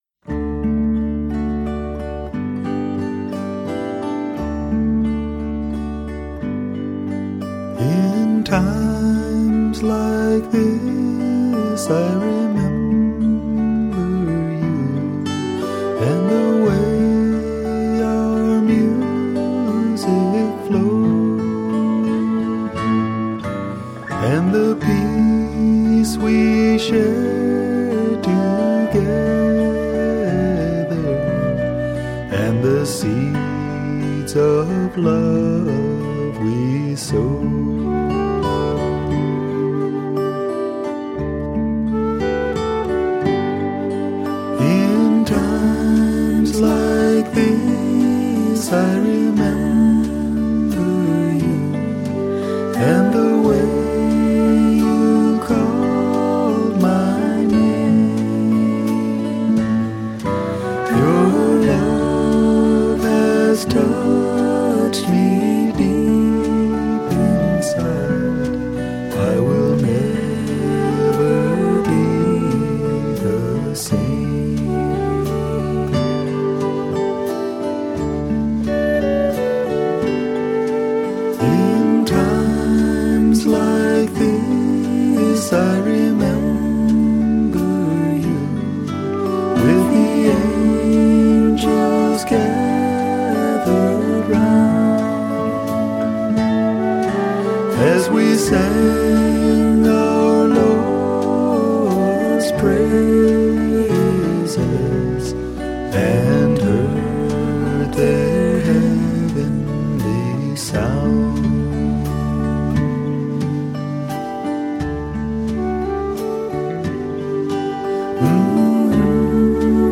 1. Devotional Songs
Major (Mohanam / Bhoop)
6 Beat / Dadra
Slow
4 Pancham / F
1 Pancham / C